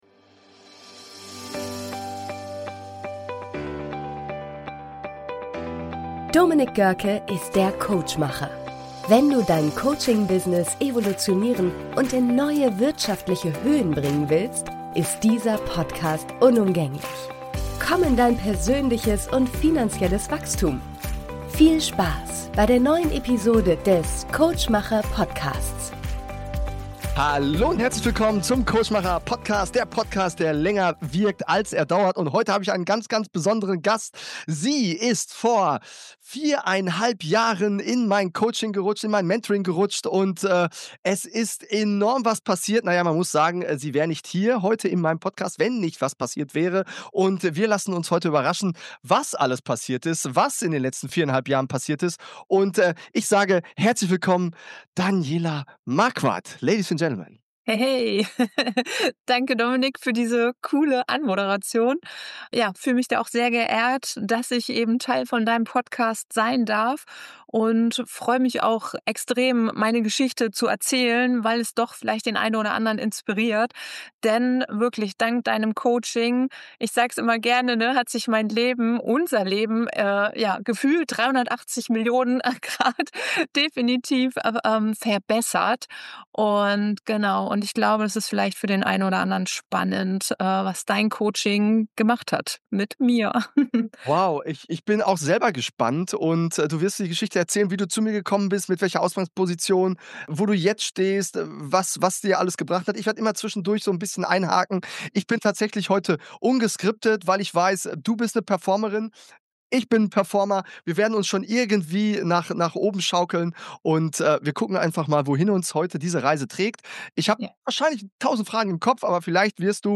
Wie du in 4,5 Jahren zu den Top 20 der Welt wirst im Network Marketing - Interview
In dieser Folge spreche ich mit einer Frau, deren Entwicklung selbst mich immer wieder beeindruckt.